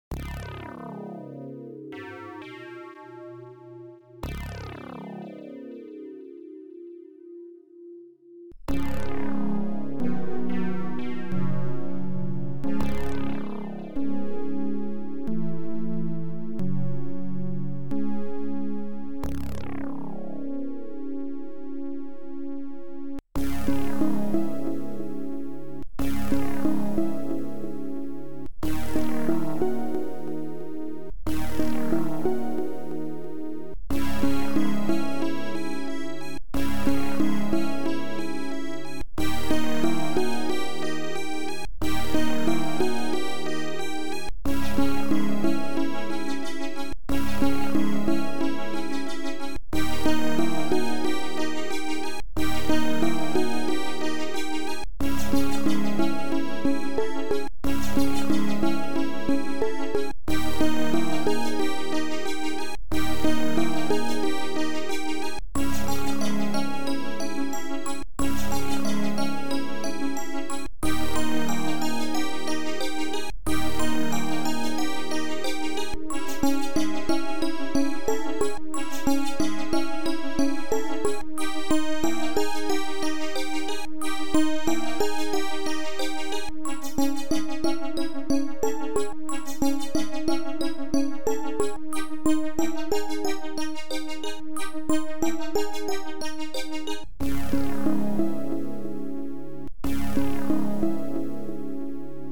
HSC AdLib Composer